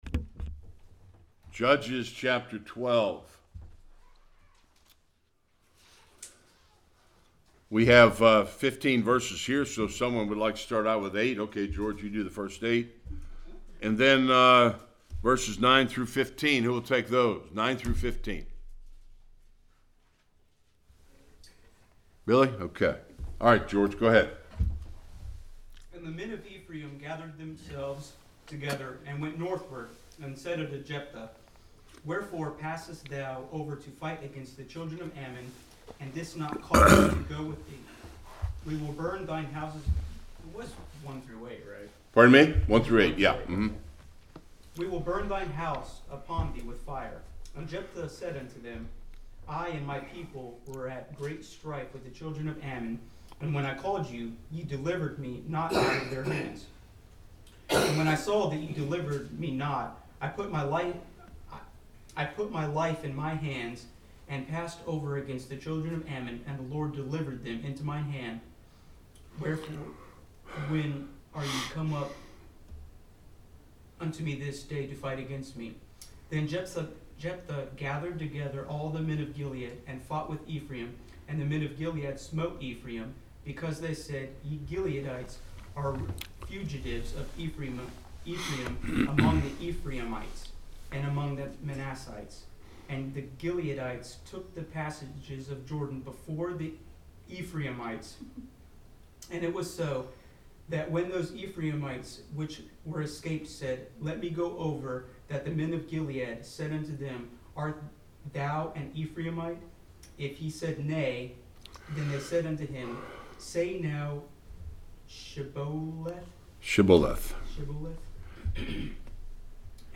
1-23 Service Type: Sunday School More of the Judges and the birth of Samson.